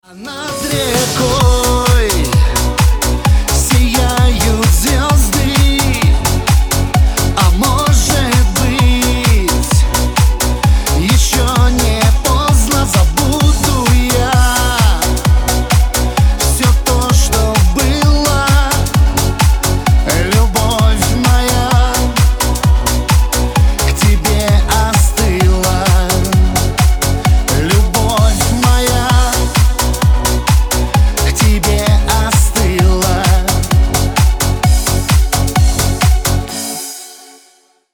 Категория: Шансон рингтоны